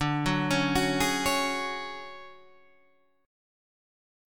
DM7b5 chord